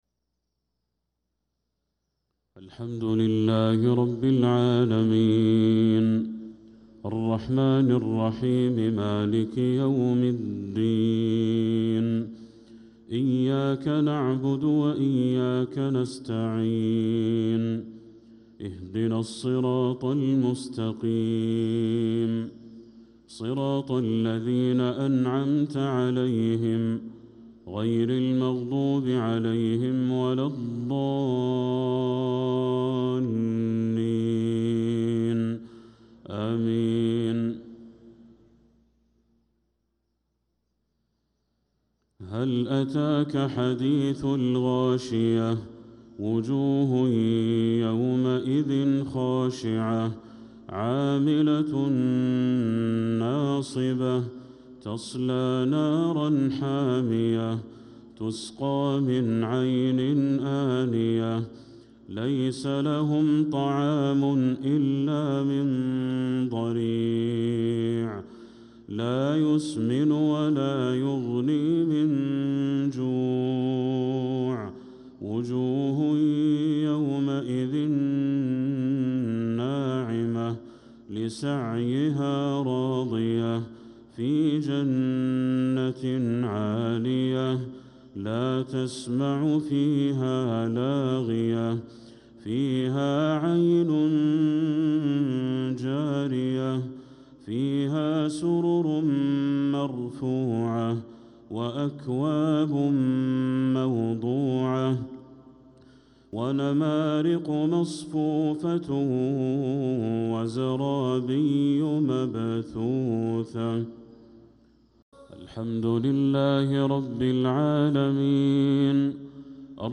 صلاة المغرب للقارئ بدر التركي 21 جمادي الأول 1446 هـ
تِلَاوَات الْحَرَمَيْن .